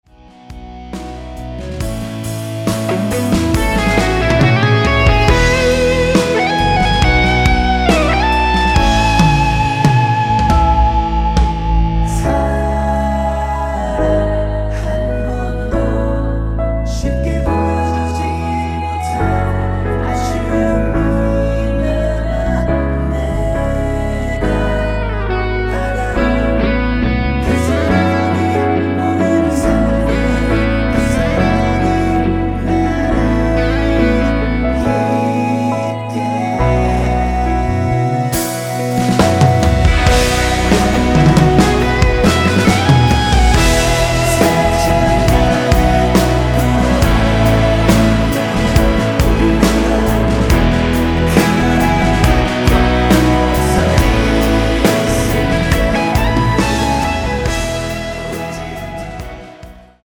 원키 멜로디와 코러스 포함된 MR입니다.(미리듣기 확인)
앞부분30초, 뒷부분30초씩 편집해서 올려 드리고 있습니다.